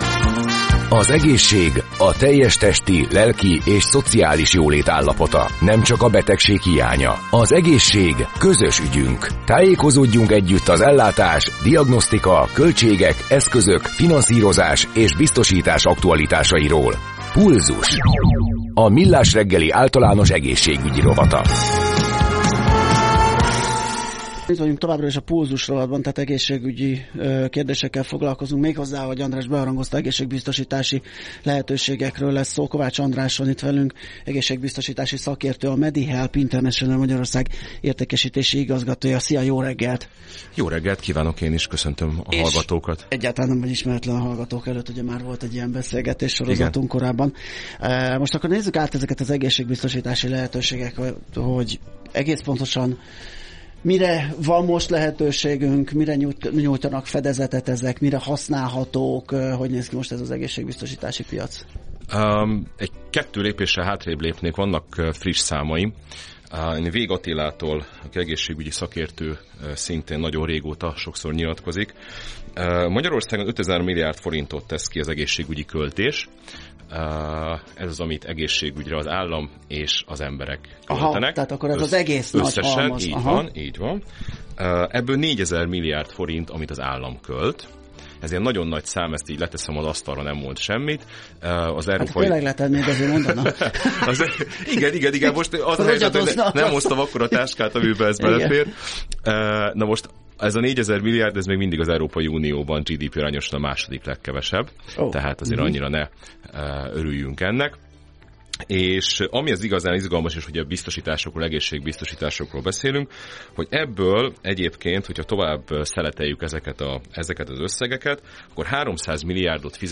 A beszélgetés rövid, de nagyon informatív: